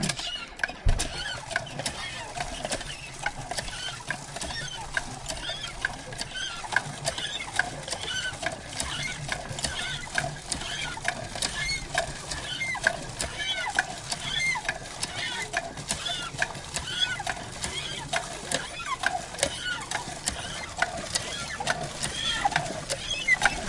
描述：这是一个带有一个坏阀的PZ型注水泵的声音。由大型CAT船用柴油发动机驱动。你可以听到强硬水中的水锤，因为它正以大约1500 PSI的速度注入井中。用iPhone录制。
标签： 场记录 油田
声道立体声